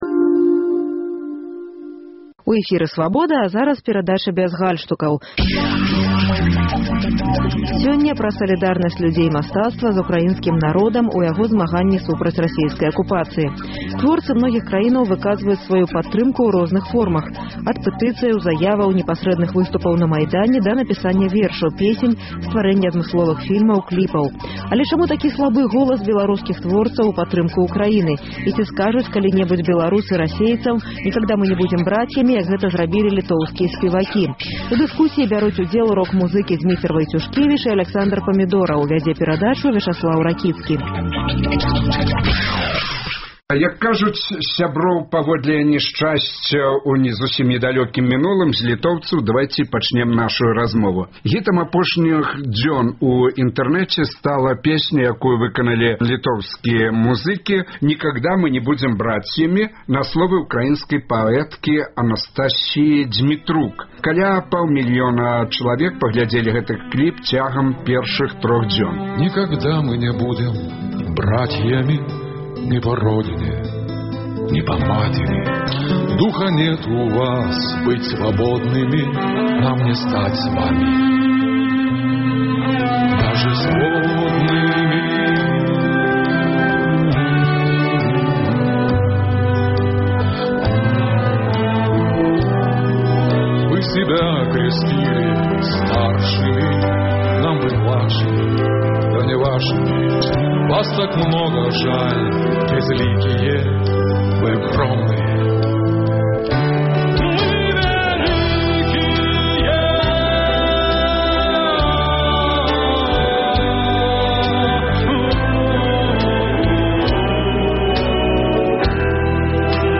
У дыскусіі бяруць удзел беларускія музыкі — Зьміцер Вайцюшкевіч і Аляксандар Памідораў.